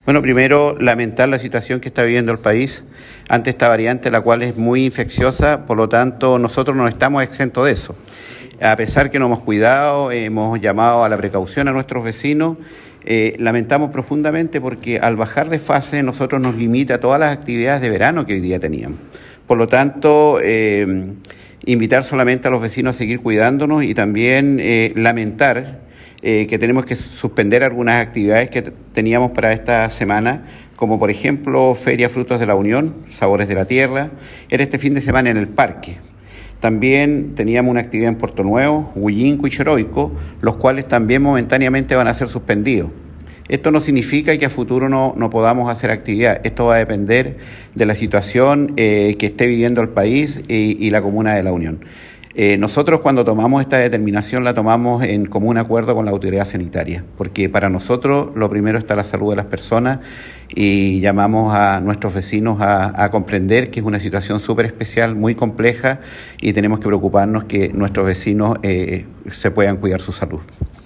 ALCALDE-X-CAMBIO-DE-FASE.m4a